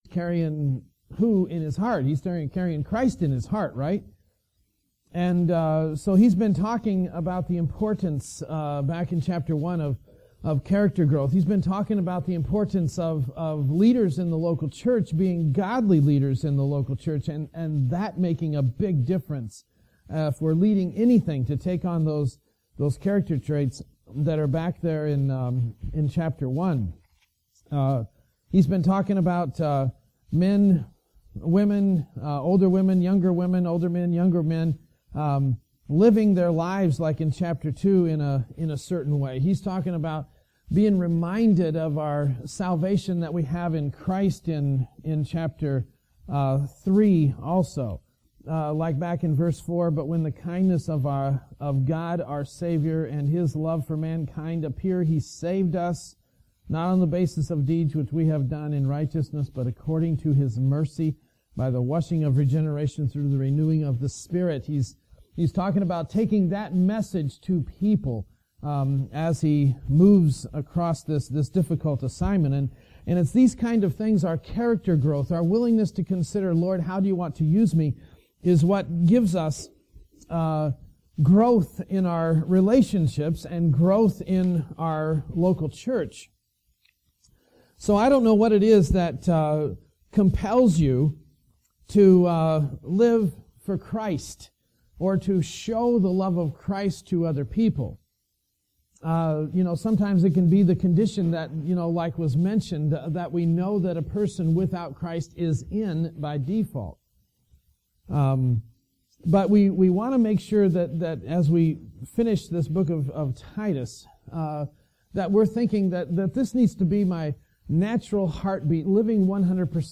Sermons from 2010